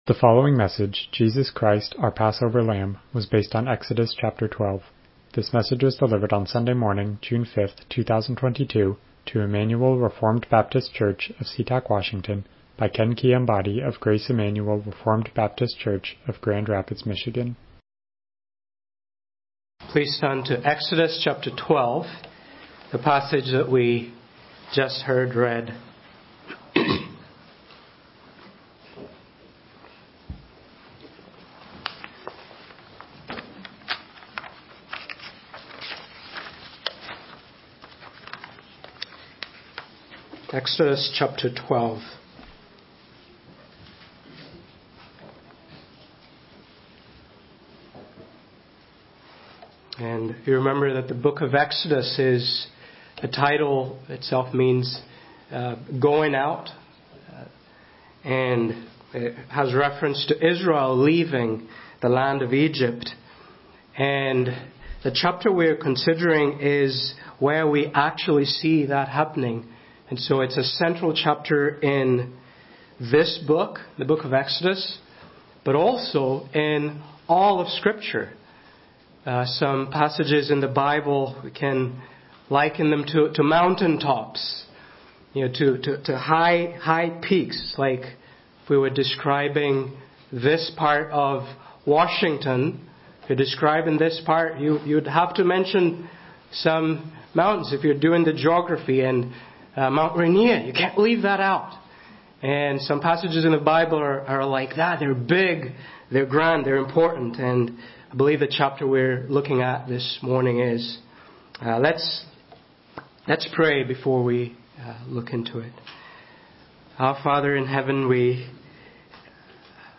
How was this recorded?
Passage: Exodus 12 Service Type: Morning Worship « Robert Murray M’Cheyne